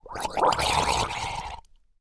Minecraft Version Minecraft Version snapshot Latest Release | Latest Snapshot snapshot / assets / minecraft / sounds / mob / drowned / idle5.ogg Compare With Compare With Latest Release | Latest Snapshot